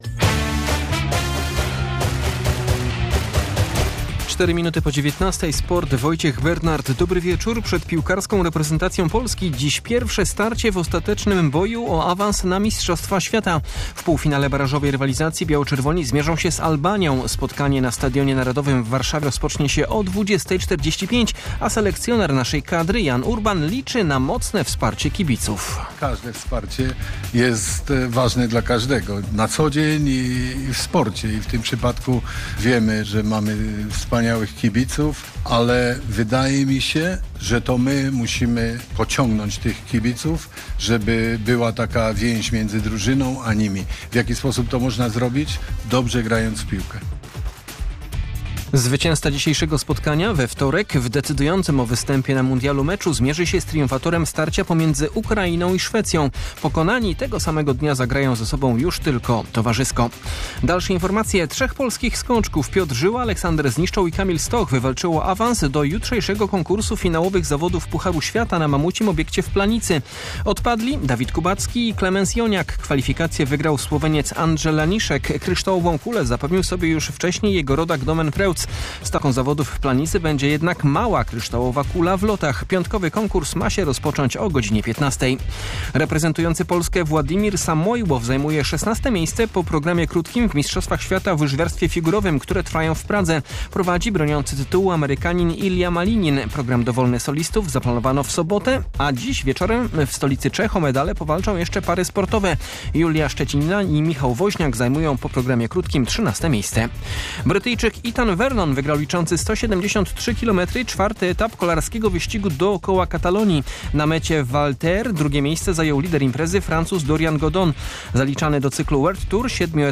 26.03.2026 SERWIS SPORTOWY GODZ. 19:05